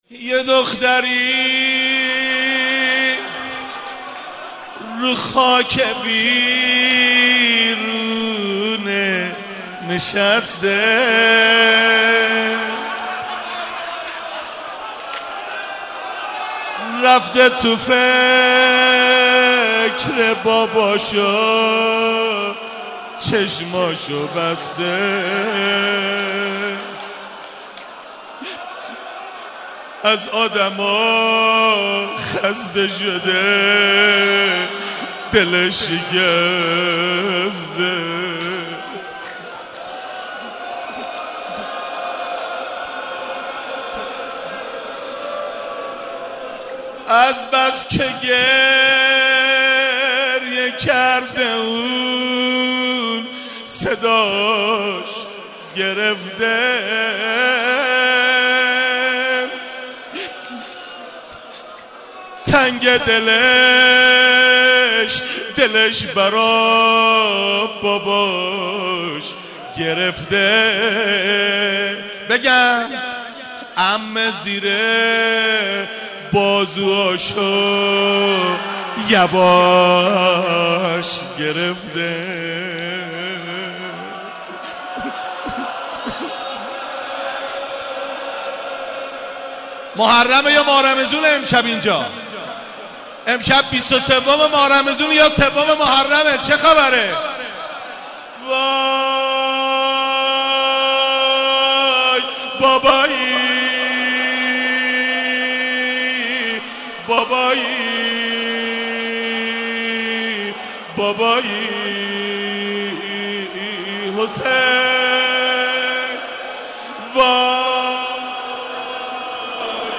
روضه و ذکر